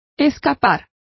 Complete with pronunciation of the translation of flee.